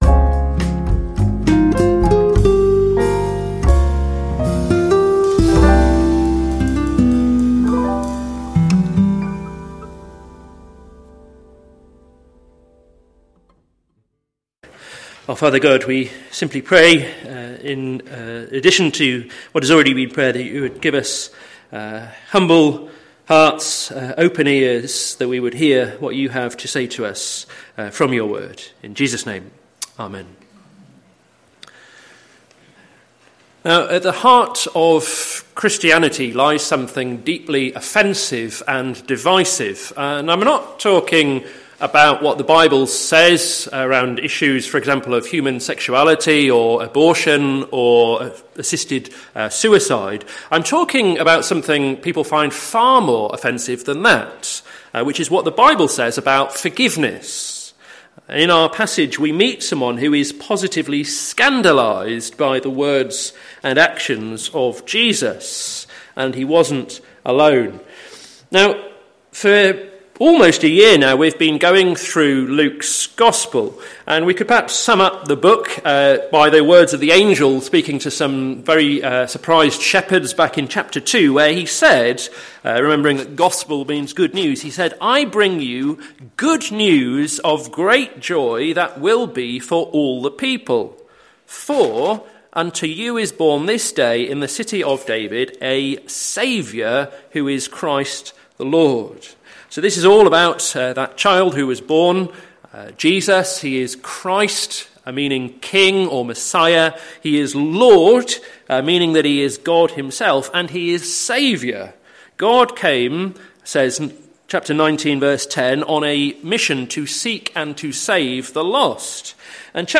Sermon Series - To Seek and to Save the Lost - plfc (Pound Lane Free Church, Isleham, Cambridgeshire)